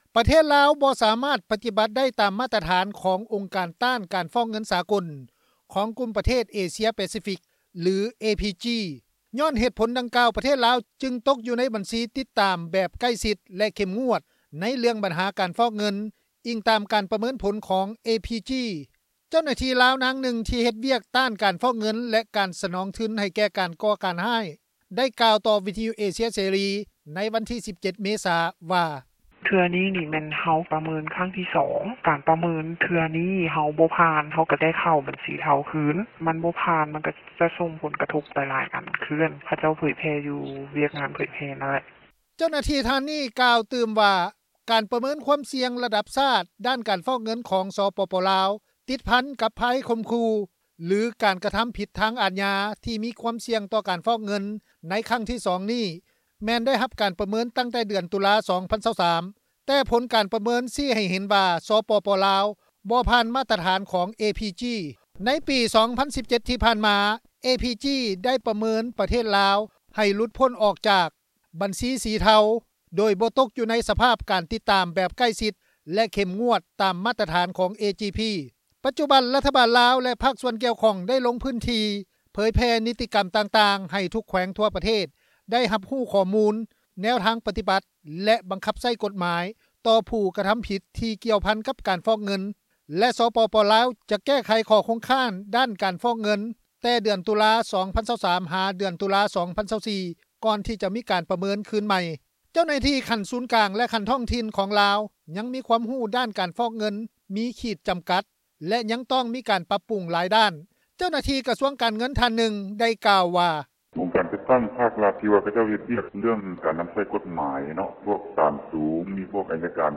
ເຈົ້າໜ້າທີ່ລາວ ນາງໜຶ່ງ ທີ່ເຮັດວຽກຕ້ານການຟອກເງິນ ແລະ ການສະໜອງທຶນ ໃຫ້ແກ່ການກໍ່ການຮ້າຍ  ໄດ້ກ່າວຕໍ່ວິທຍຸເອເຊັຽເສຣີ ໃນວັນທີ 17 ເມສາ ວ່າ:
ເຈົ້າໜ້າທີ່ກະຊວງການເງິນ ທ່ານໜຶ່ງ ໄດ້ກ່າວວ່າ:
ຊາວລາວ ທ່ານໜຶ່ງຢູ່ເມືອງຕົ້ນເຜິ້ງ ແຂວງ ບໍ່ແກ້ວ ໄດ້ກ່າວຕໍ່ ວິທຍຸເອເຊັຽເສຣີ ວ່າ: